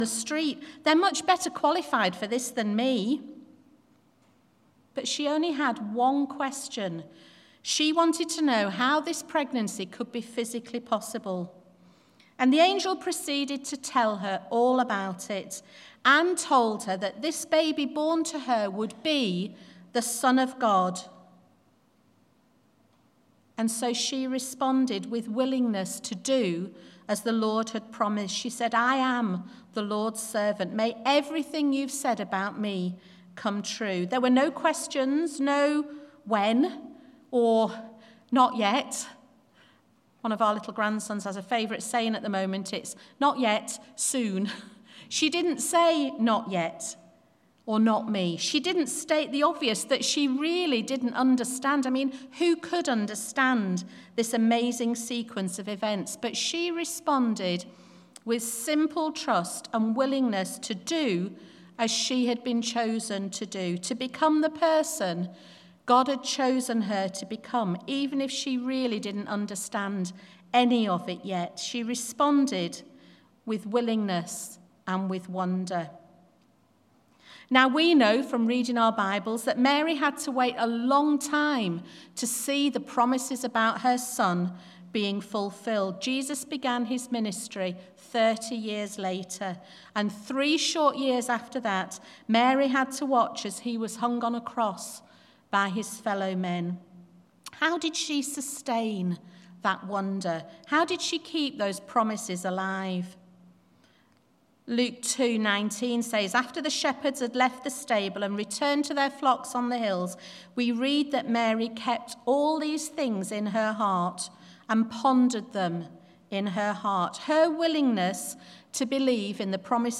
Bible Text: Luke 1:26-38, Matthew 1:18-25, Luke 2:1-20, Matthew 2:1-12 | Preacher